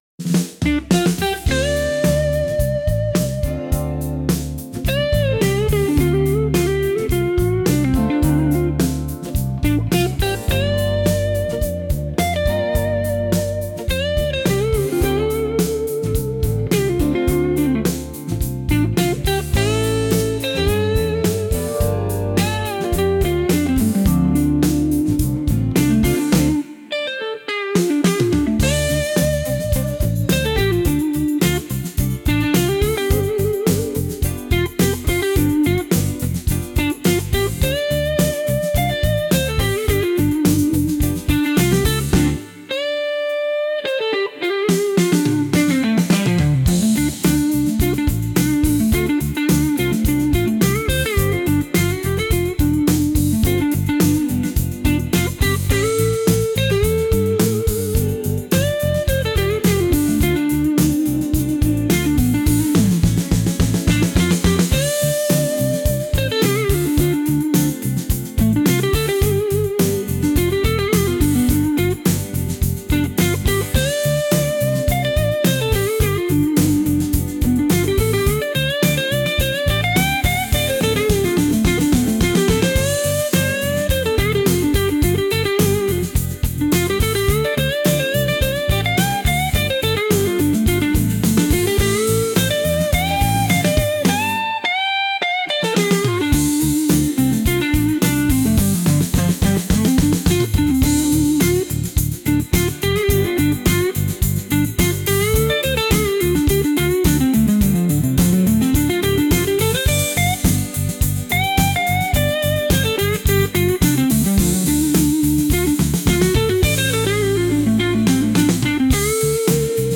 Instrumental - Blues Real Liberty Media 5.39 .mp3